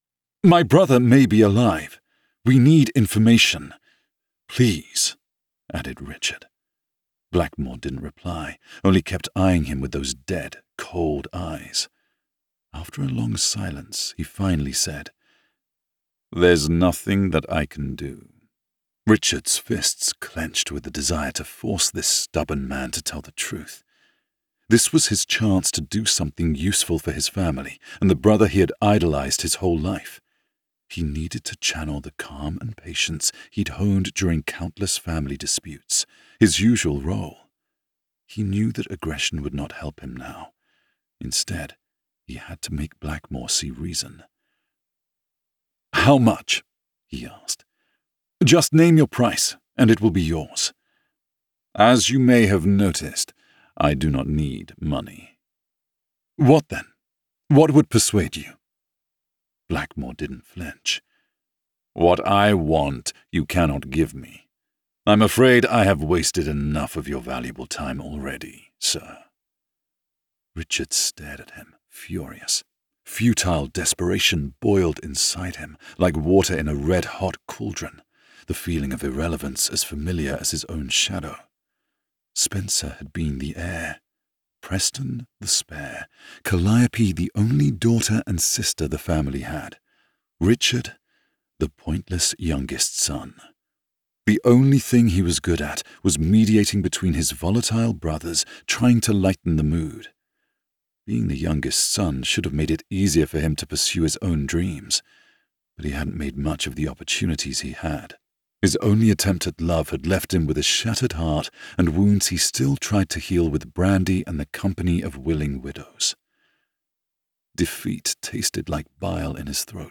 Audiobooks
Her-rake-fiance-retail-sample-1.mp3